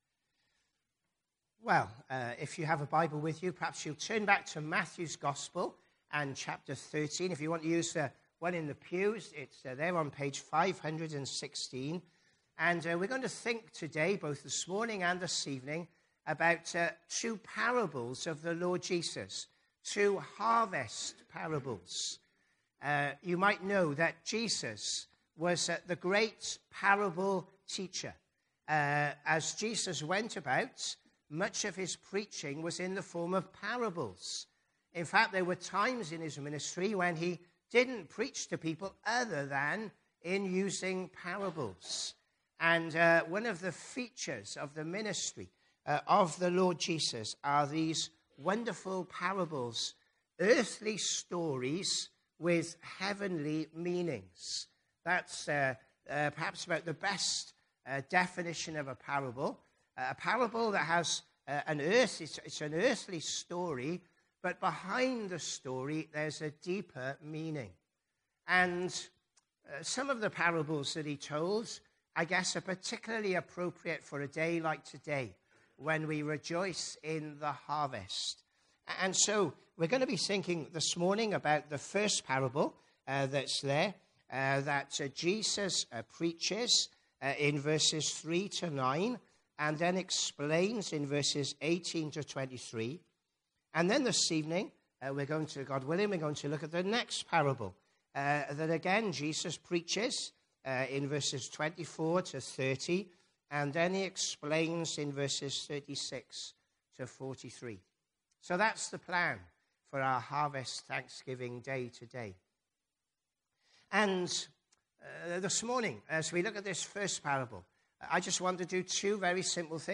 AM Service